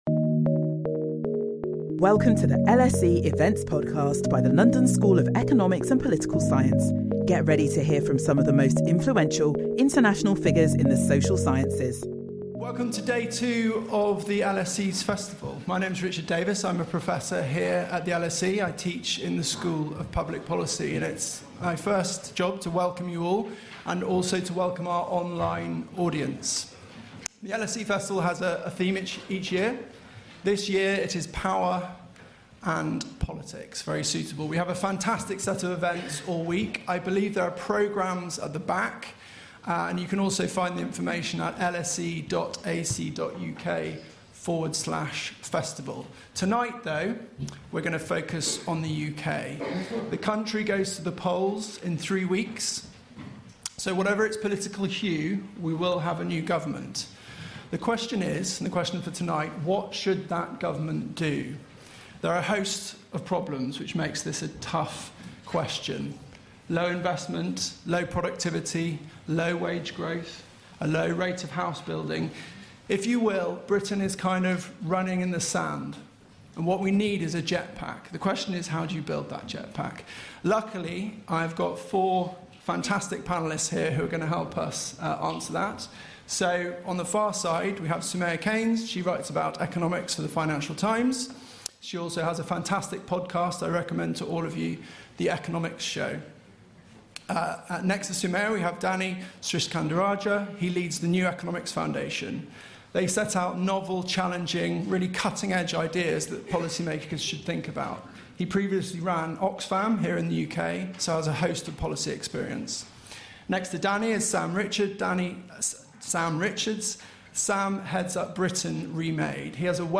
Our panel examine Britain’s economic model and how it can be re-built to kickstart productivity and tackle the country’s challenges.